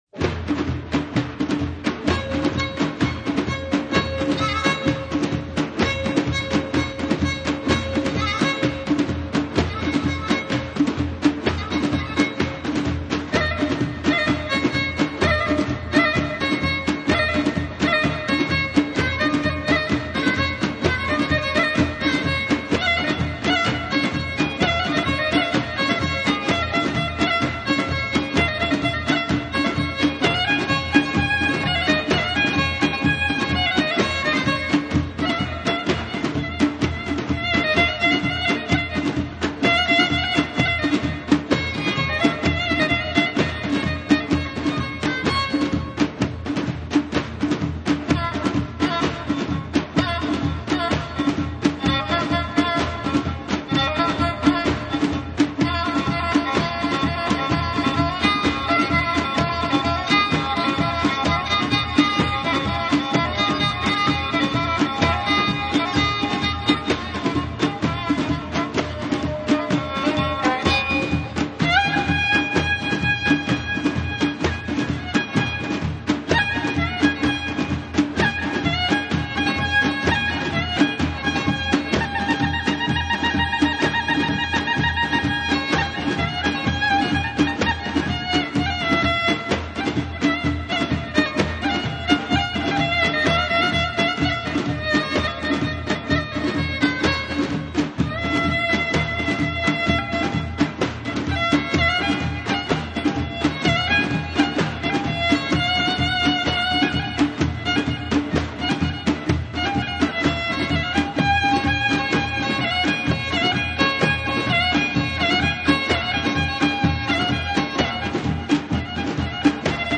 イラン中部ロレスターン州の舞曲（音声）